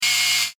Photo_zoom.ogg